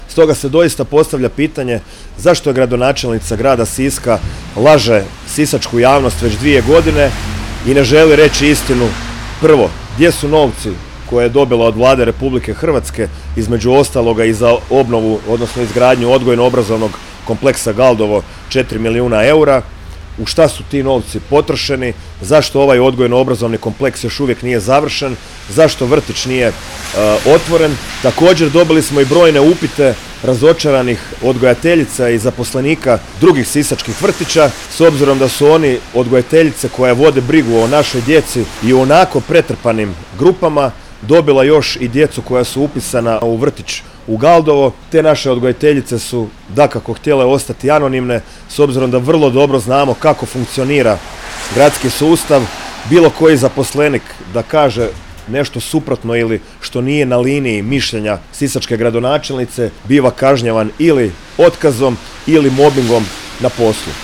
Gradska organizacija HDZ-a Sisak održala je u petak, 22. studenog 2024. godine, tiskovnu konferenciju ispred odgojno-obrazovnog kompleksa u Galdovačkoj ulici, na temu: „Poziv na otvorenje vrtića u Galdovu”.